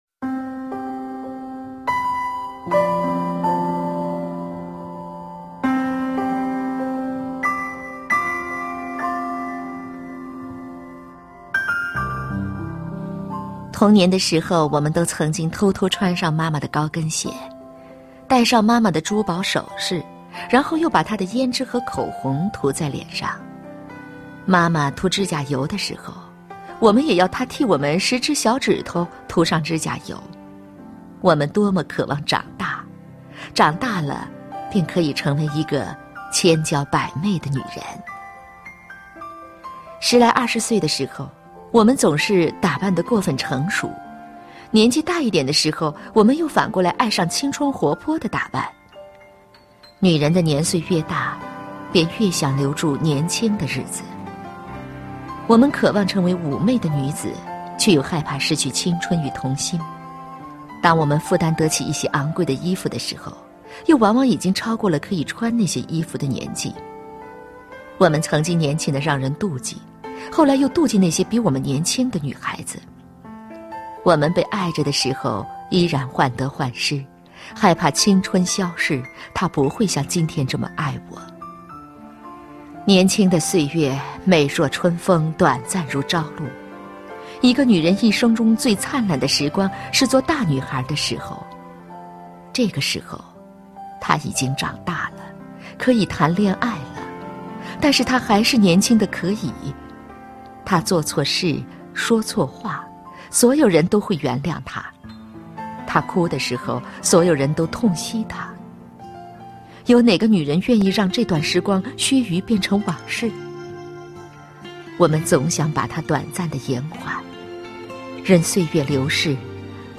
首页 视听 经典朗诵欣赏 张小娴：爱，从来就是一件千回百转的事